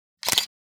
camera_capture.wav